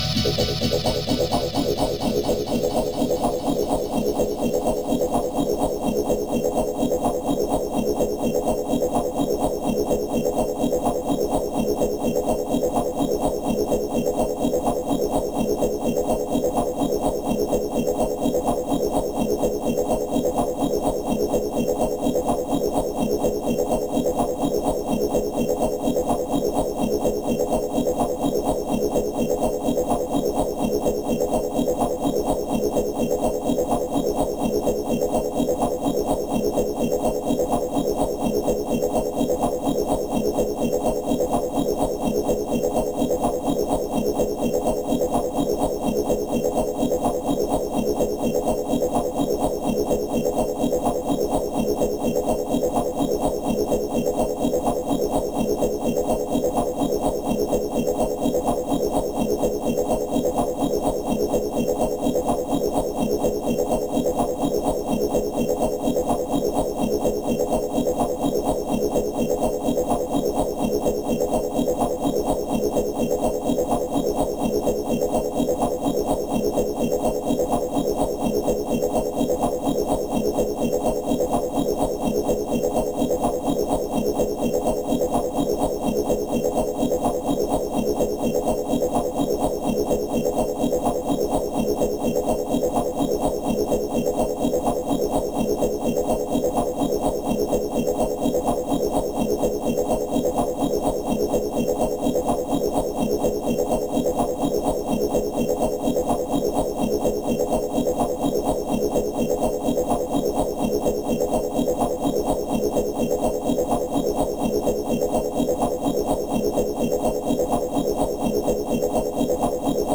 • rave sequence black riot 1 - E - 126.wav
Royalty free samples, freshly ripped from a rompler, containing sounds of the early rave and hardcore from the 90′s. These can represent a great boost to your techno/hard techno/dance production.
rave_sequence_black_riot_1_-_E_-_126_o2W.wav